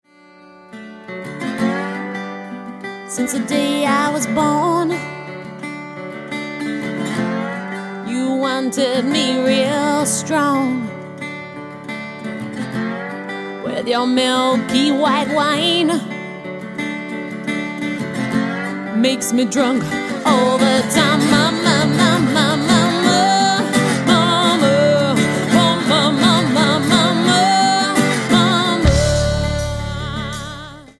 Alternative,Blues,Folk